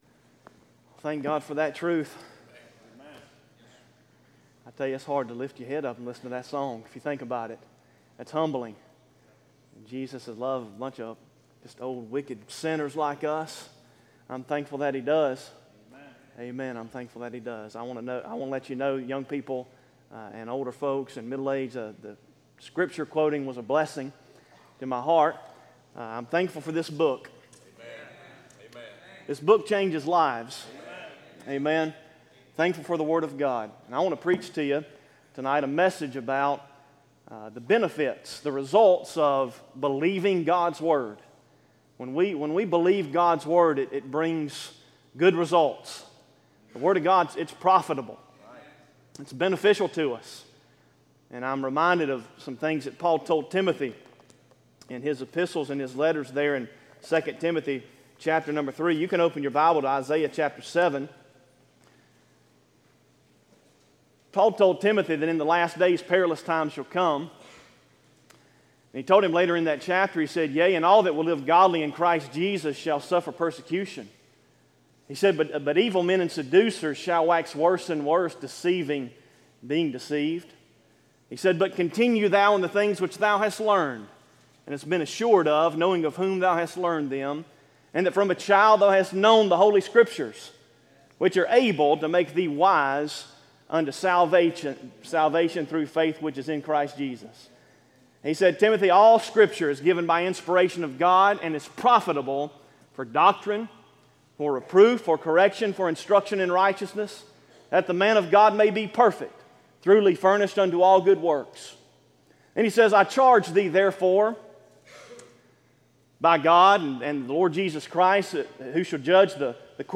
Sermons Archive • Page 3 of 185 • Fellowship Baptist Church - Madison, Virginia
series: Candlelight Service